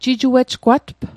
Pronunciation Guide: ji·ju·ech·kwatp